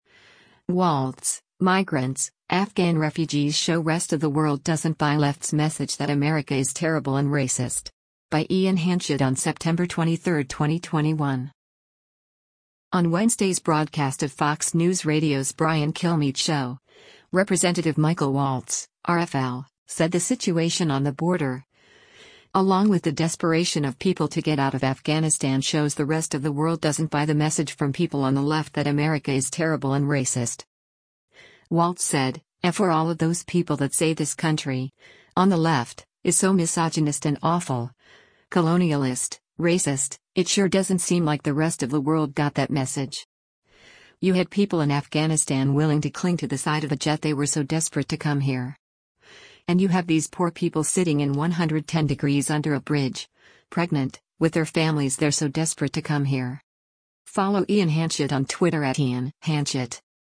On Wednesday’s broadcast of Fox News Radio’s “Brian Kilmeade Show,” Rep. Michael Waltz (R-FL) said the situation on the border, along with the desperation of people to get out of Afghanistan shows the rest of the world doesn’t buy the message from people on the left that America is terrible and racist.